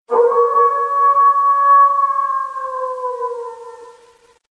voracious-sigh.mp3